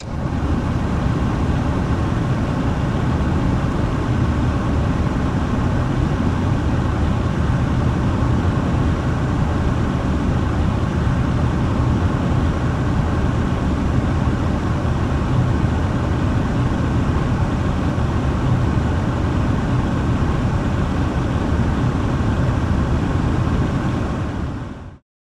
Ford LTD interior point of view as A/C runs at low then high speed. Vehicles, Sedan Air Conditioner